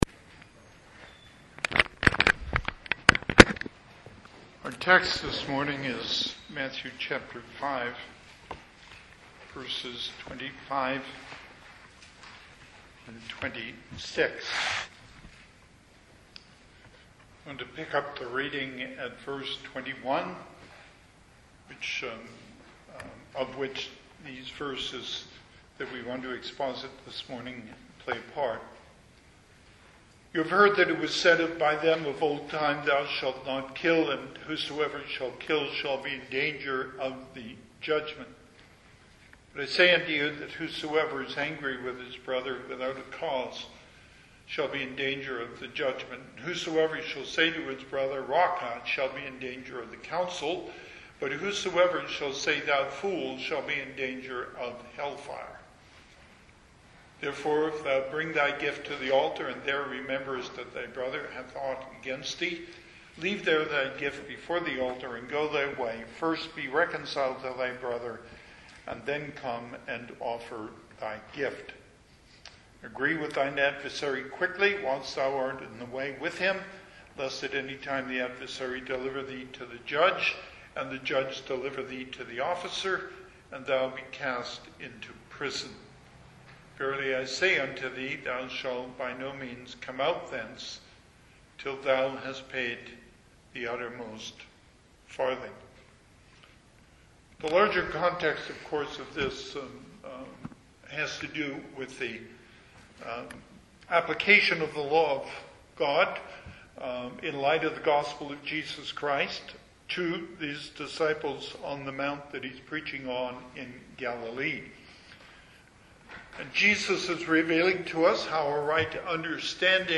Passage: Matthew 5:25-26 Service Type: Sunday AM